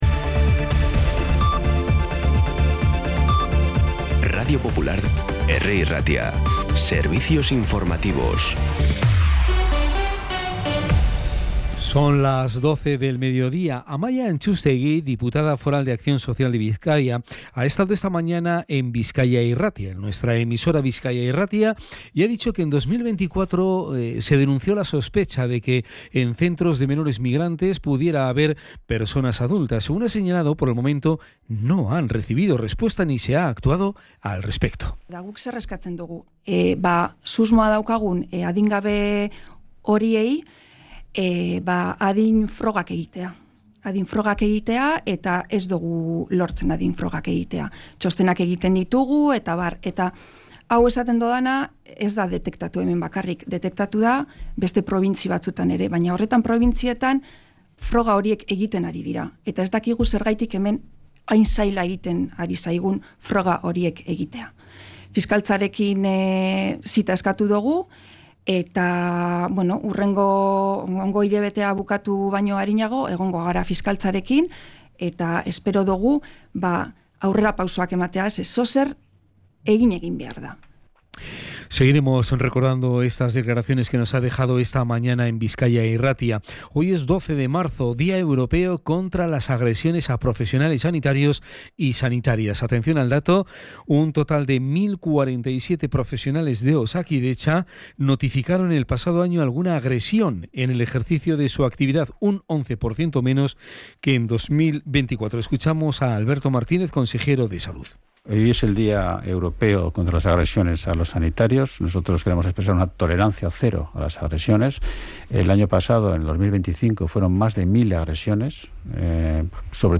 Las noticias de Bilbao y Bizkaia del 12 de marzo a las 12
Los titulares actualizados con las voces del día.